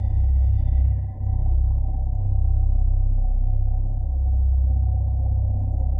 科学幻想无人机 " 无人机09 - 声音 - 淘声网 - 免费音效素材资源|视频游戏配乐下载
科幻无人机，用于室内或室外房间的音调，气氛，外星人的声音，恐怖/期待的场景.